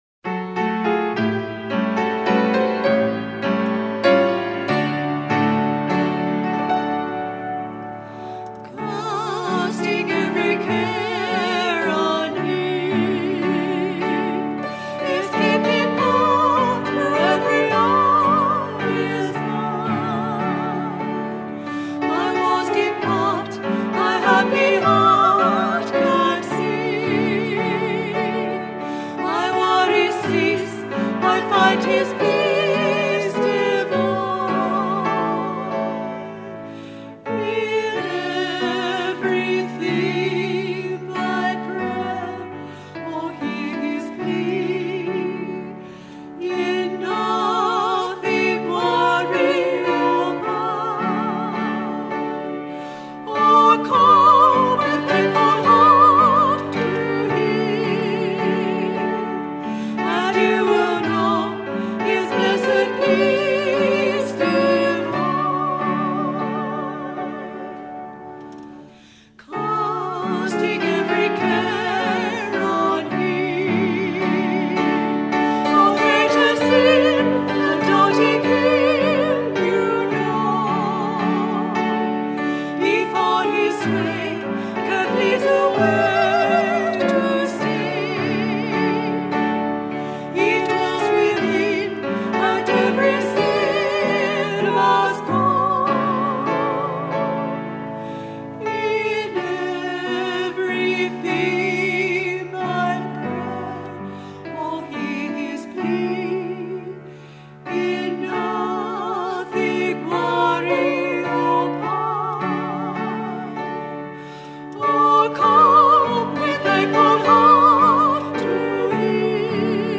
Singing
Date: 2001-11-18 06:00 Length: 2 min Location: Canberra national